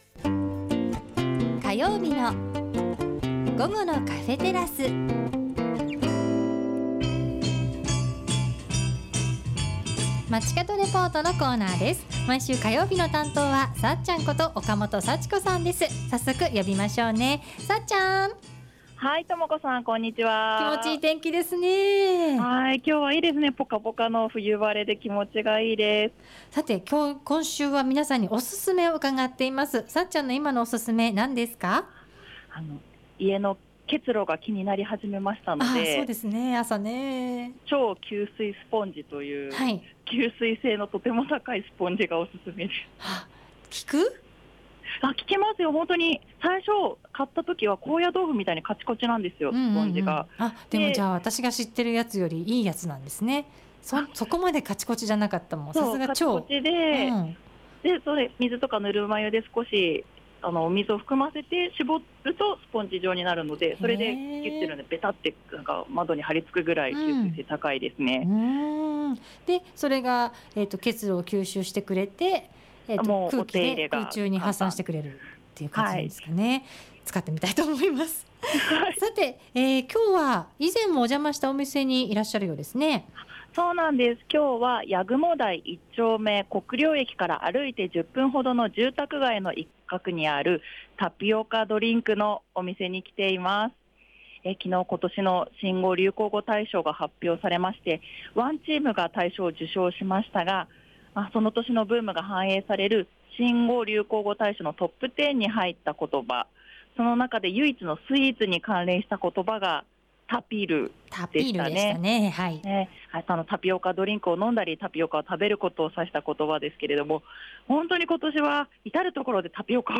タピオカは今年、大変なブームとなり、 調布市内にも今年に入って何軒もタピオカドリンク店がオープンして行列をよく目にしました。 そこで師走に入った今日は、今年の代名詞のタピオカドリンクのお店から中継しました♪